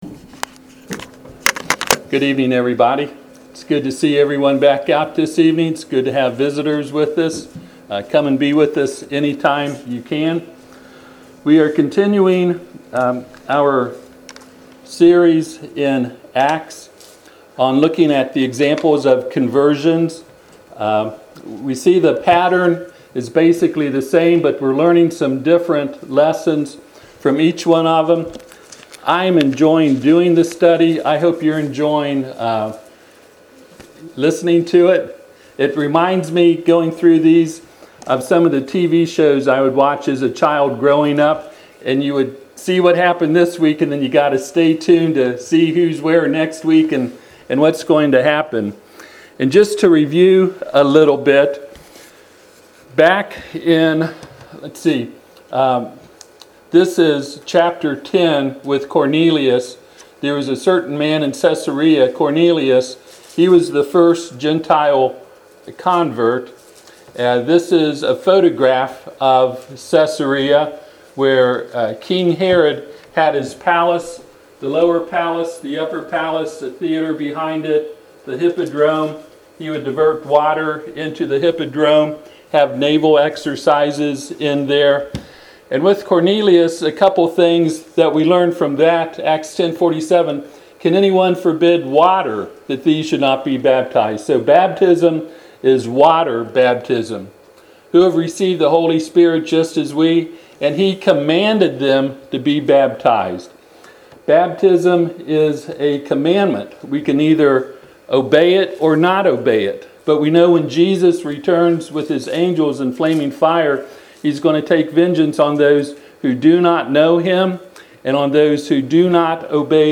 Service Type: Sunday PM Topics: Baptism , Faith , Repentance , Salvation